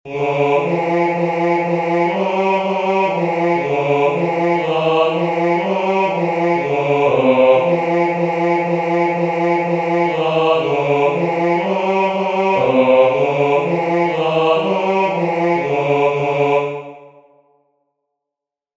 "In omnem terram," the first antiphon from the first nocturn of Matins, Common of Apostles